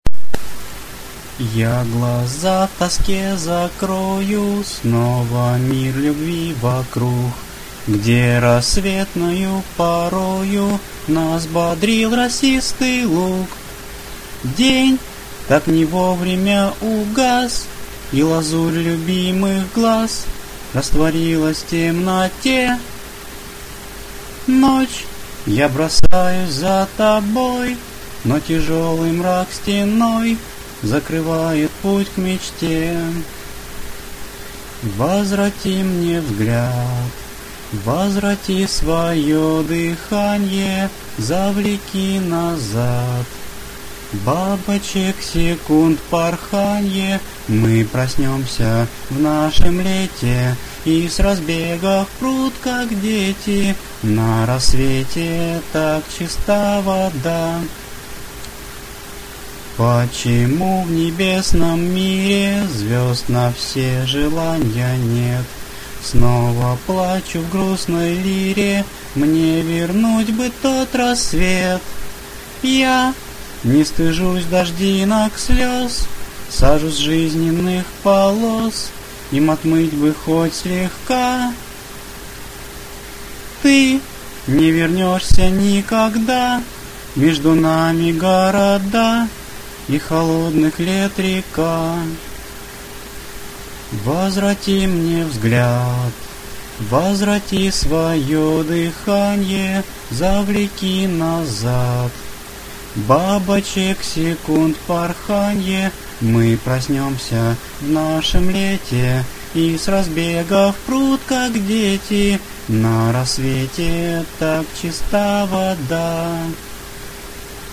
Песня
Прослушать в авторском исполнении (только вокал):